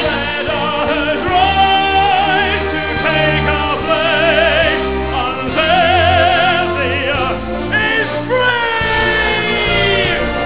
his voice is so commanding